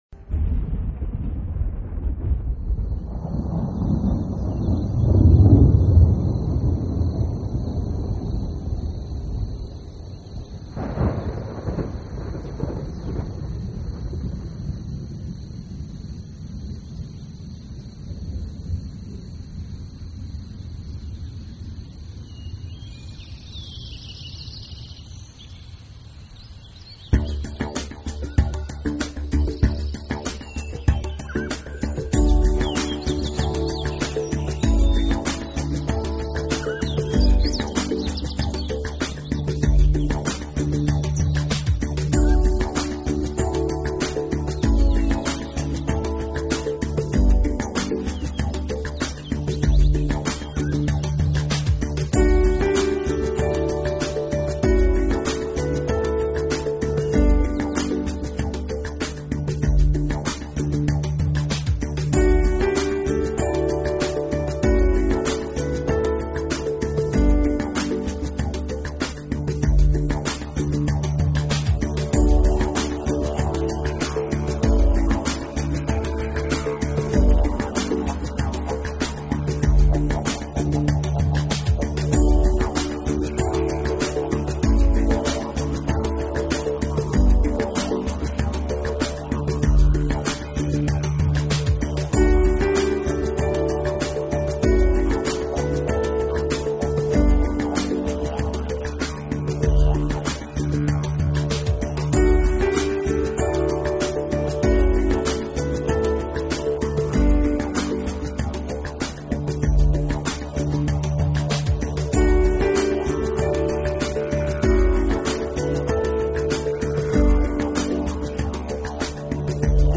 语言：纯音乐
专辑风格：新时代 New Age
优美的旋律伴着海浪声、海鸥的叫声，营造出大自然的气息。